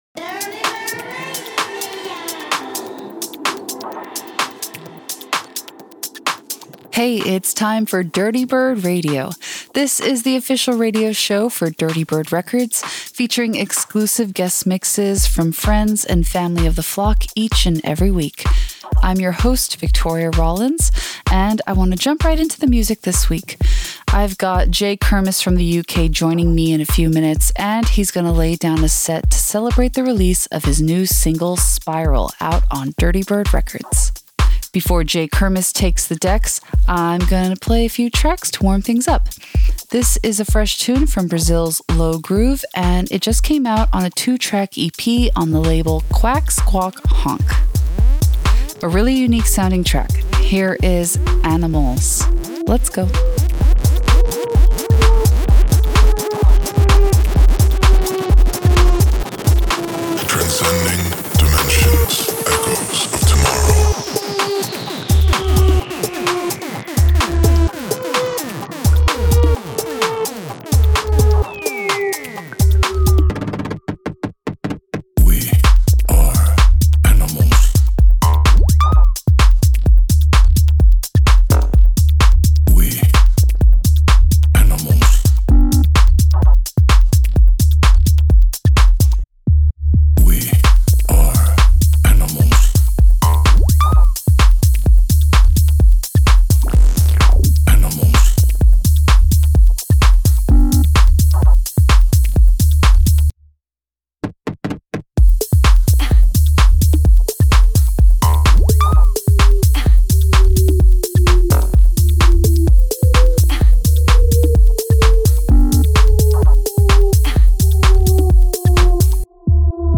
alternative bass music to funk based house and electronica
Electronic